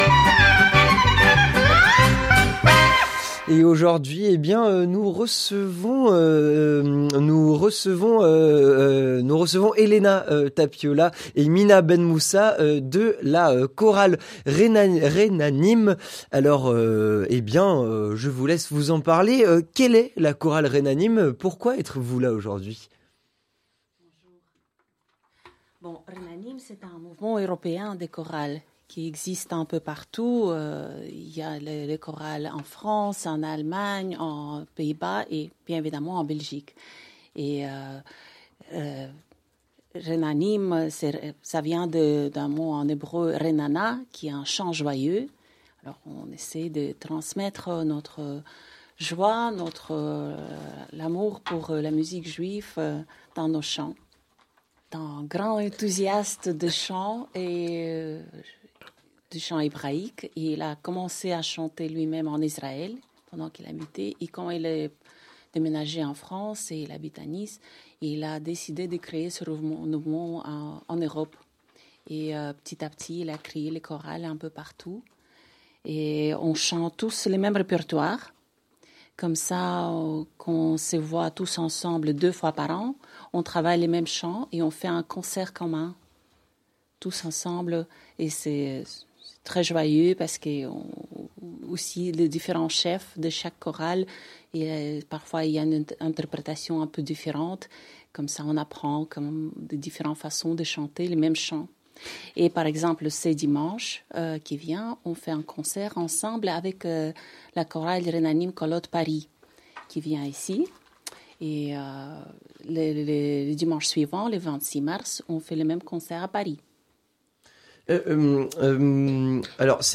Interview communautaire - La chorale Renanim